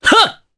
Roi-Vox_Jump_jp.wav